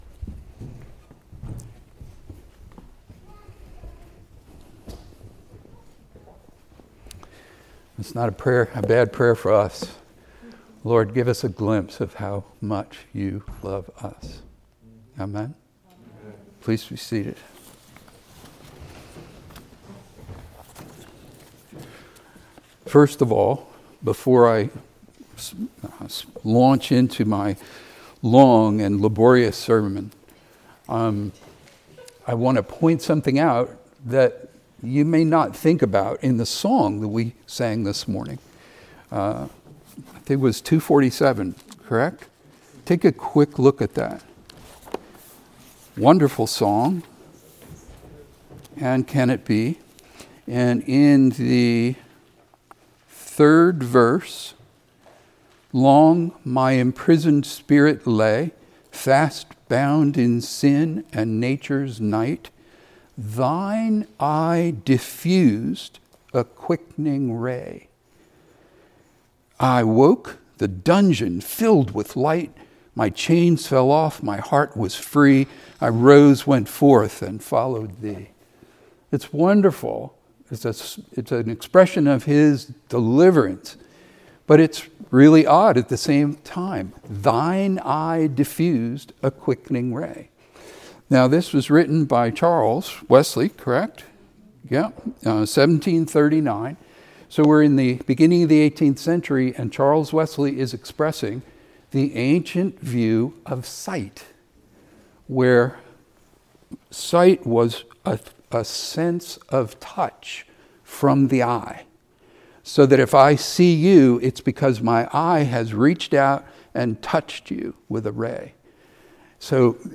Sermons | Anglican Church of the Ascension